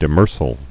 (dĭ-mûrsəl)